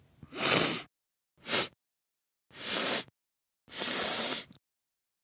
Descarga de Sonidos mp3 Gratis: aspirar olfato.
snif-4.mp3